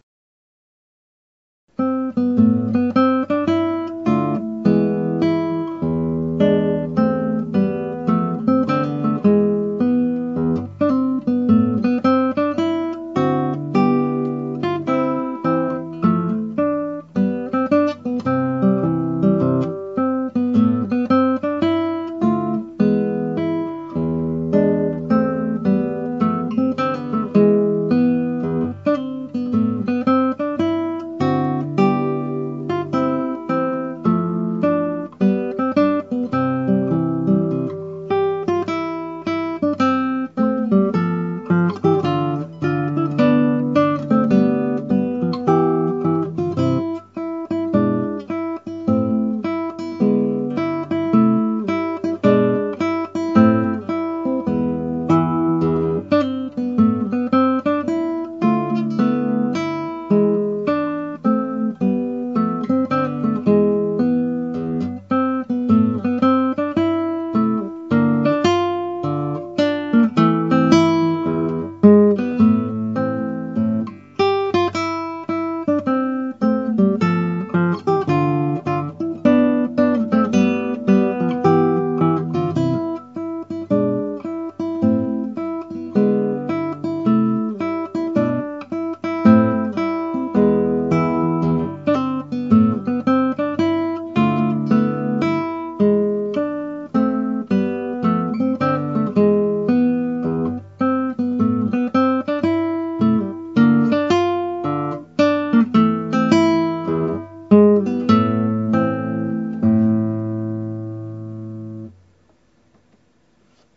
(アマチュアのクラシックギター演奏です [Guitar amatuer play] )
速さ指定はAndanteです。
小さなやさしい曲ですが二声の弾き分けやリズム感を養うのに手ごろな曲だと思います。